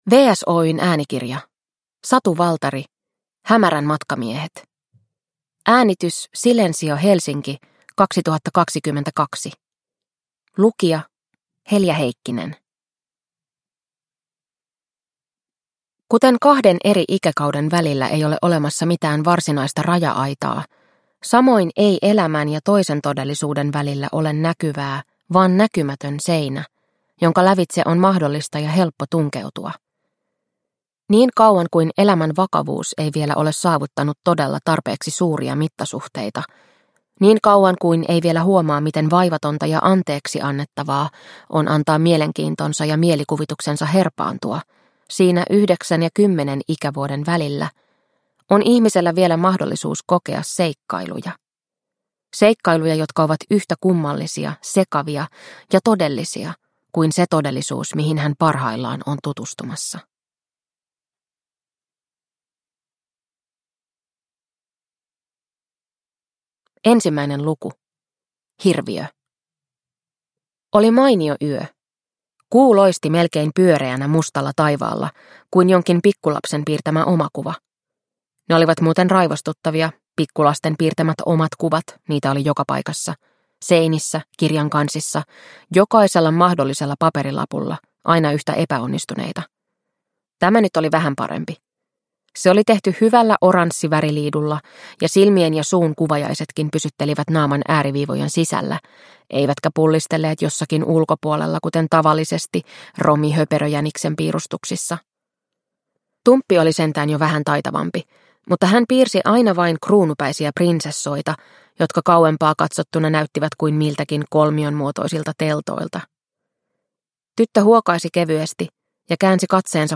Hämärän matkamiehet – Ljudbok – Laddas ner